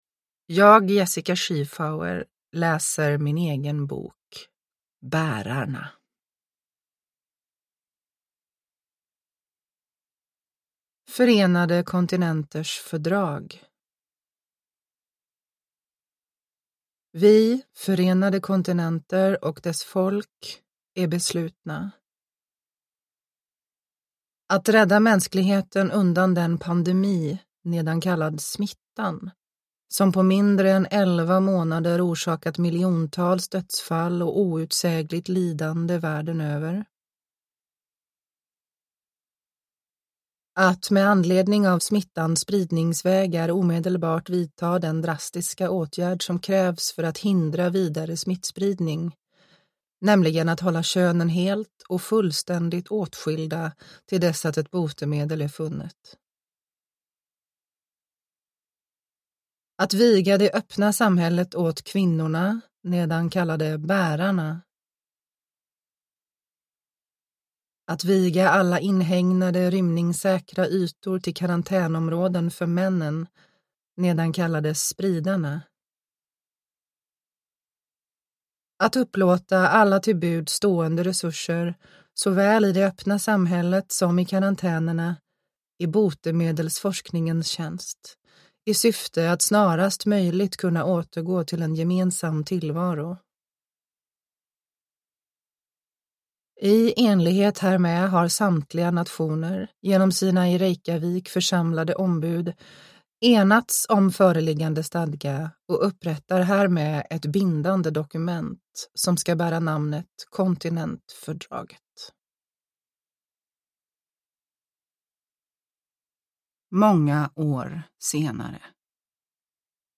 Uppläsare: Jessica Schiefauer
Ljudbok